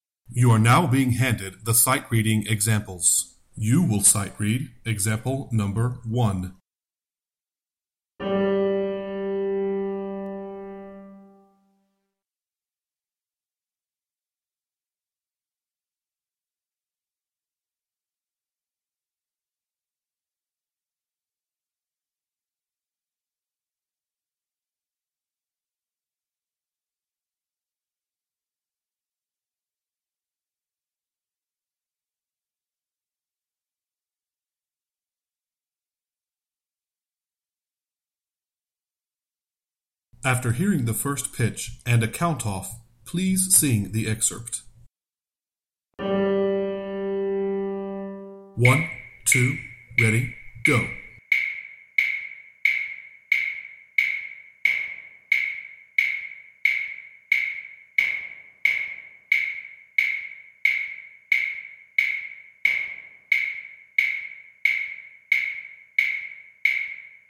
SightreadingExample-2013-Tenor1.mp3